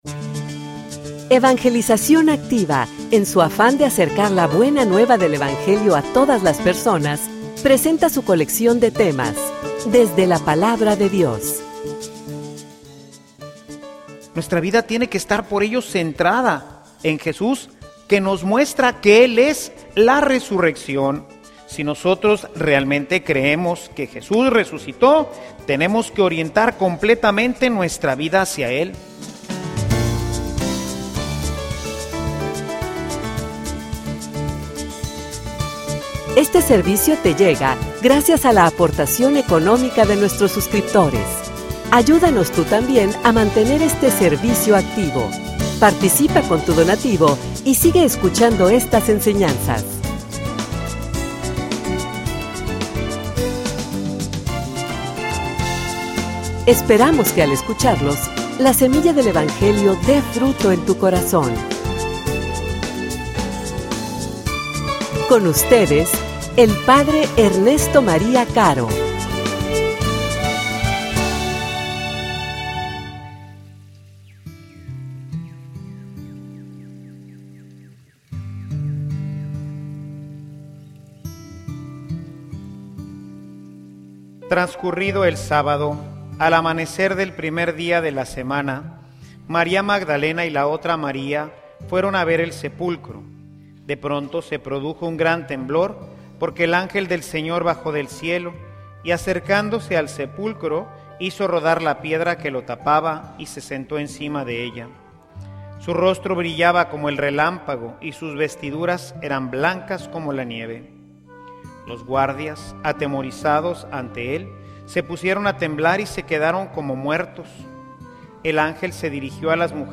homilia_Nuestra_pascua_testimonio_de_vida.mp3